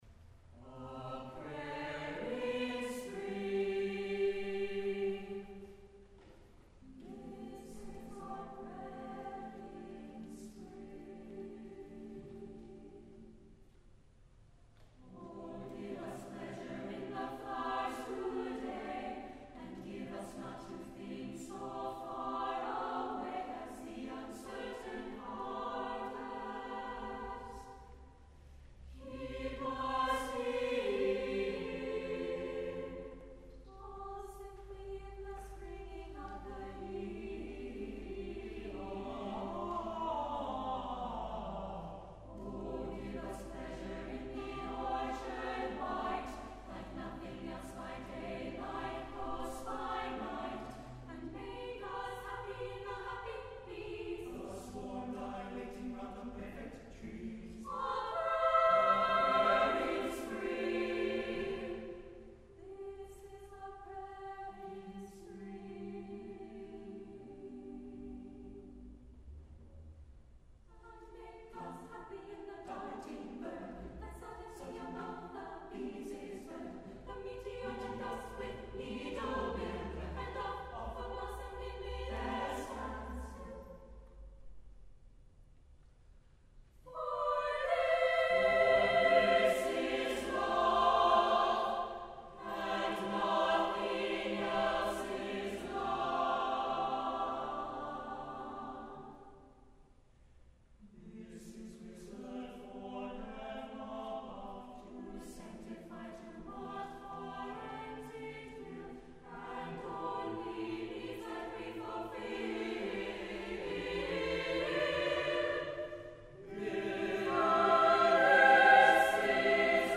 for SATB Chorus (2012)